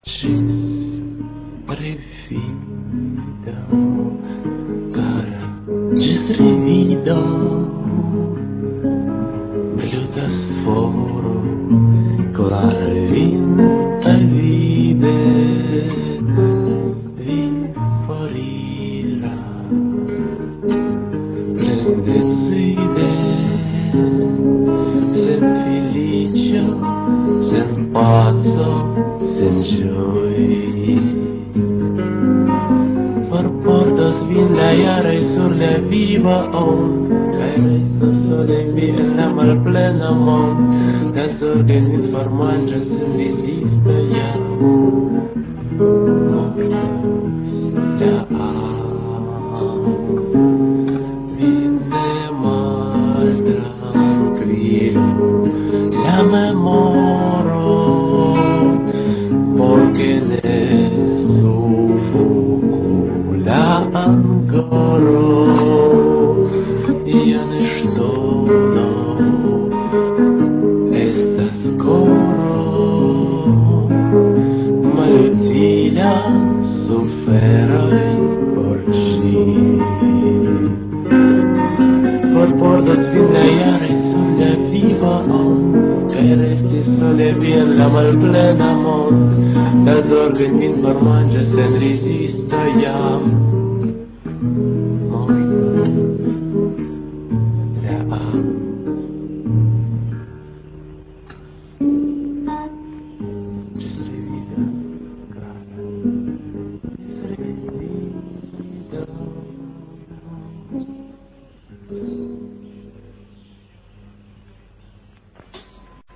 Rusperanta fokloro